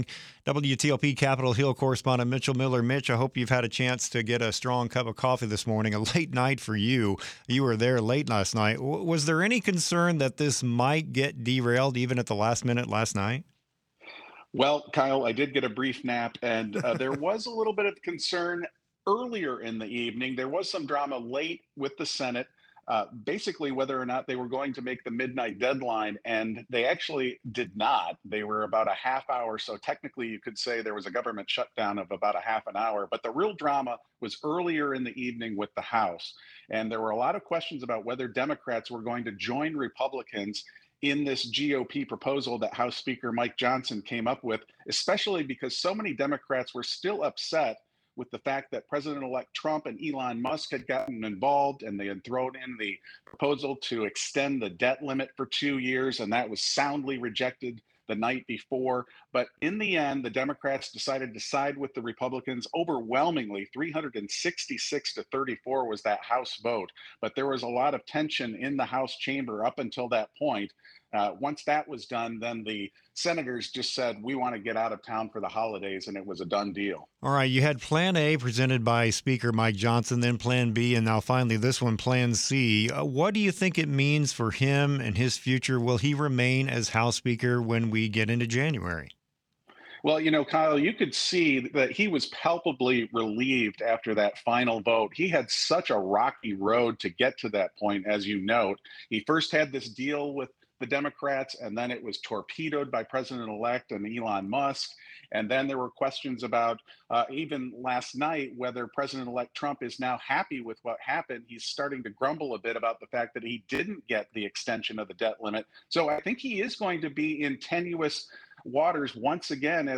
Listen to the full interview below or read the transcript, which has been lightly edited for clarity.